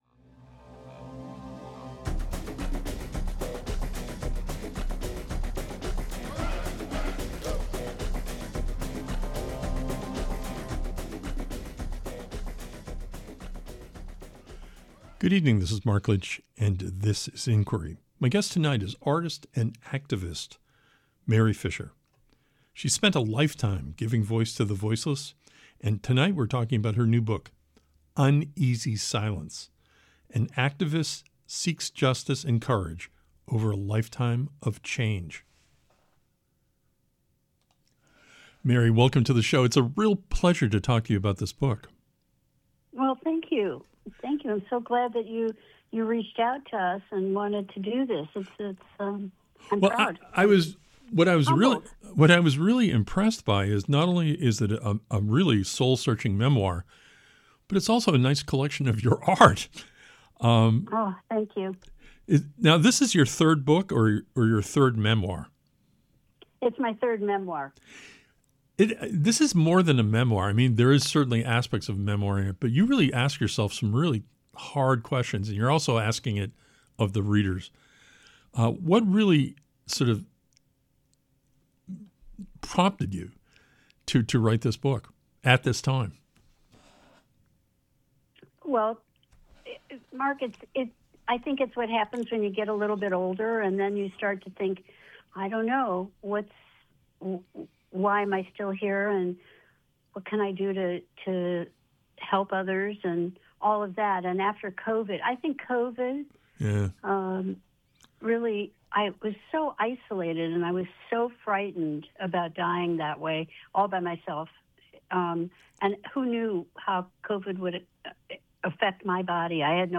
Tonight on Inquiry welcomes artist and activist MARY FISHER.